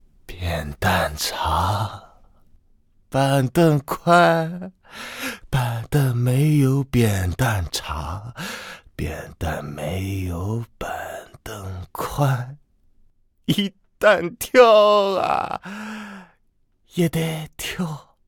c02_疯子对话圆月亮3.ogg